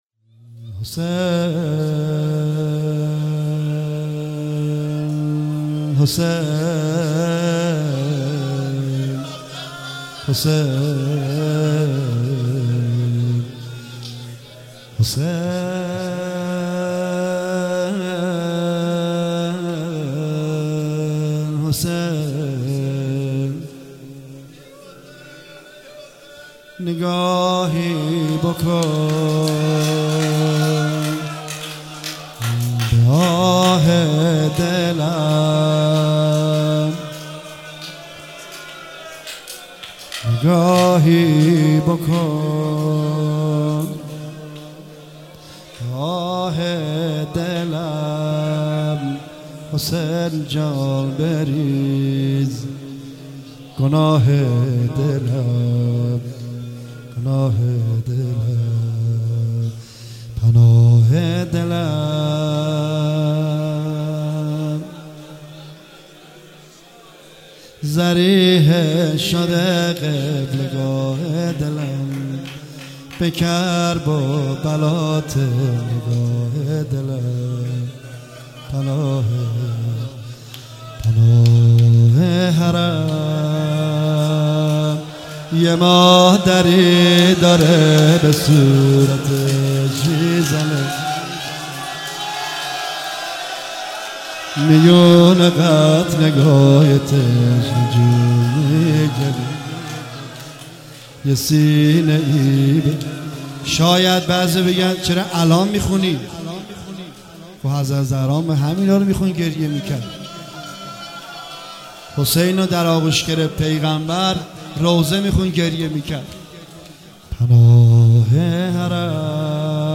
شب اول محرم سال 95/هیت رزمندگان مکتب الحسین(ع)
روضه پایانی